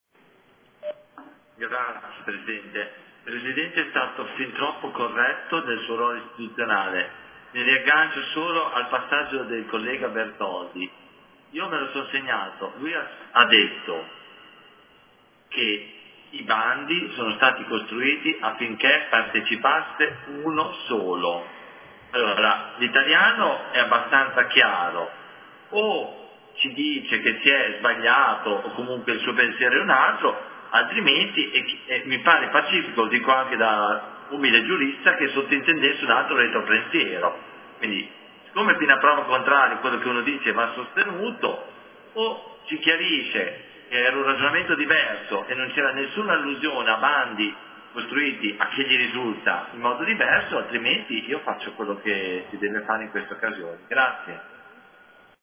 Antonio Carpentieri — Sito Audio Consiglio Comunale
Seduta del 02/07/2020.